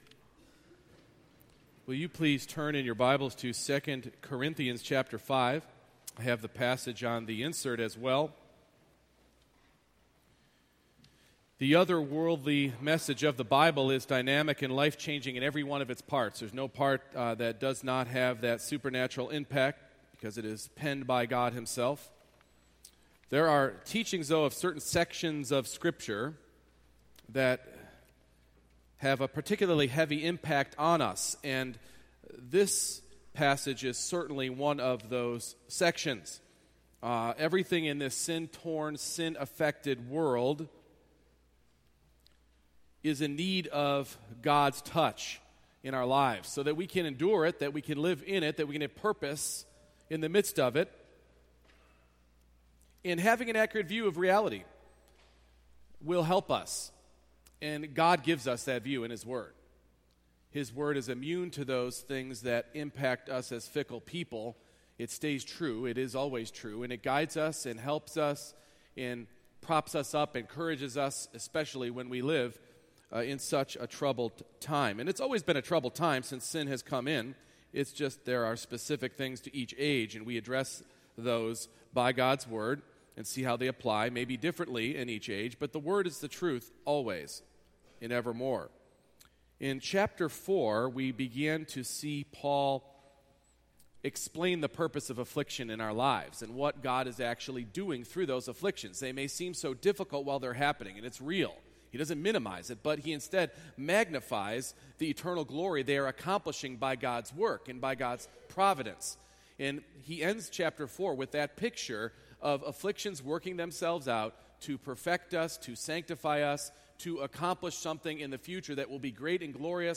2 Corinthians 5:1-10 Service Type: Morning Worship Embracing the reality of a future resurrection life will encourage us to live for Christ in the present.